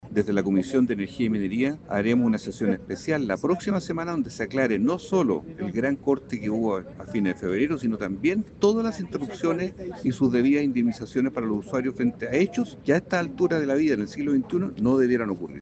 En el Congreso, el senador socialista y presidente de la Comisión de Minería y Energía, Juan Luis Castro, reiteró que nuestro sistema “no ha estado a la altura” por lo que se desarrollará una sesión especial la próxima semana para discutir las seguidillas de cortes registrados en el último tiempo.